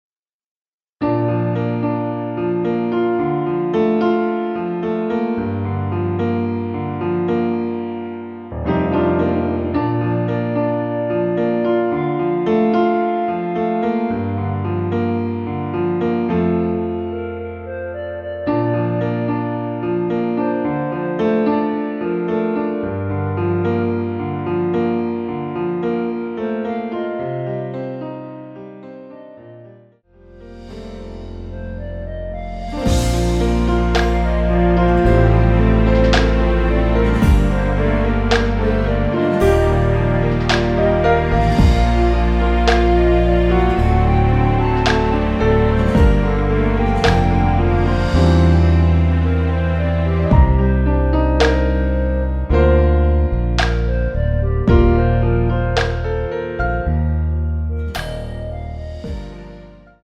멜로디 포함된 MR 입니다.(미리듣기 참조)
Bb
앞부분30초, 뒷부분30초씩 편집해서 올려 드리고 있습니다.
중간에 음이 끈어지고 다시 나오는 이유는